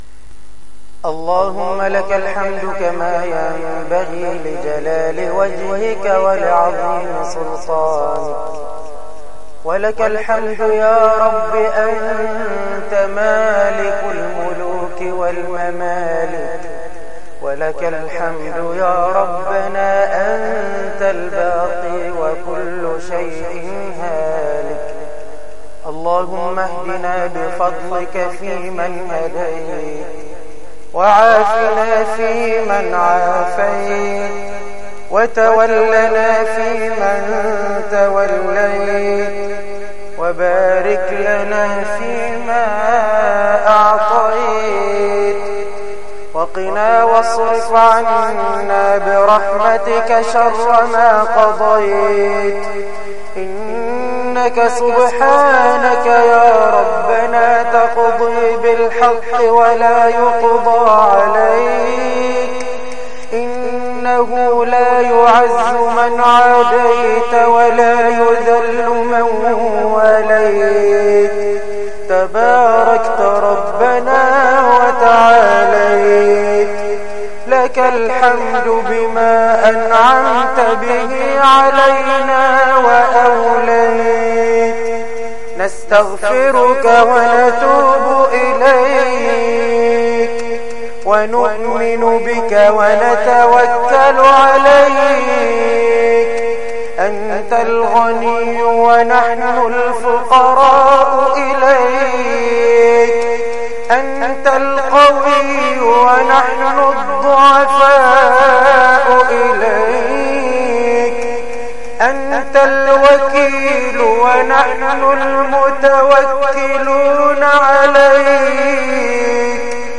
الدعاء
دعاء خاشع ومؤثر
تسجيل لدعاء خاشع ومميز